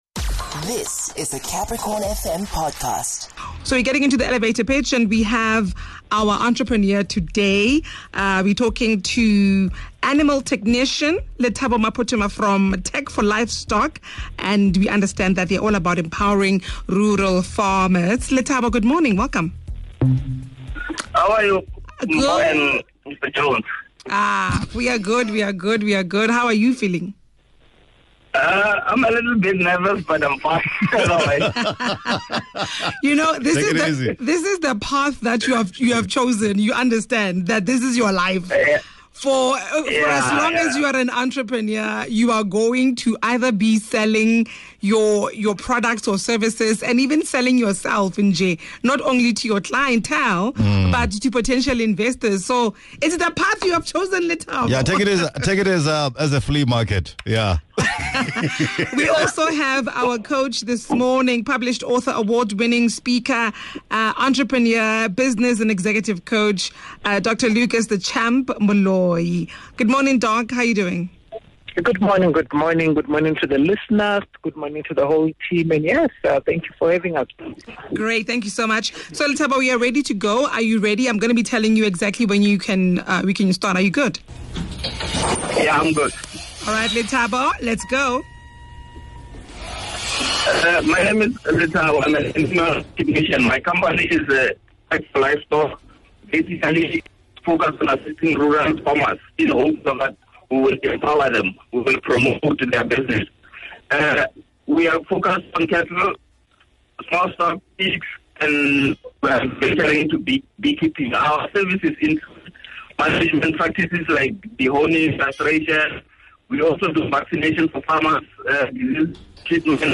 He pitched his business in 30 seconds.